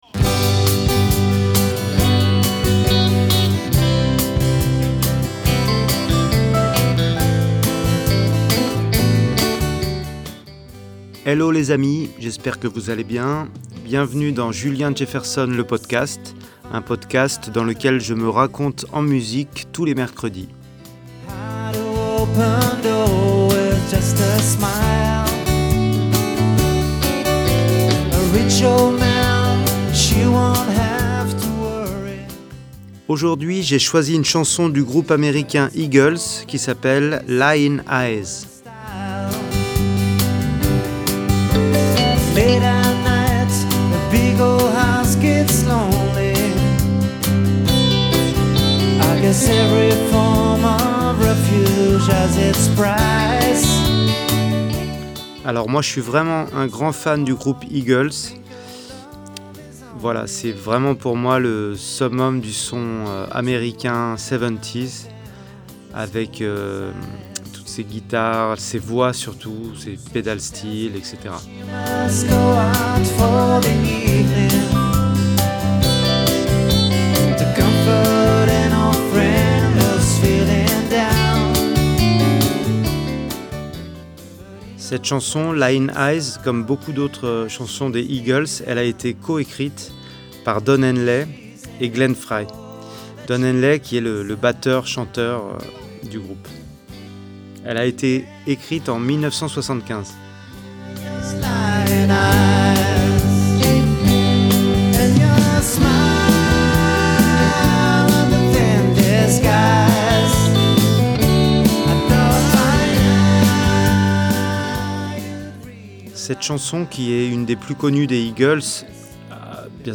et nous en livre une version live enregistrée au Supersonic à Paris.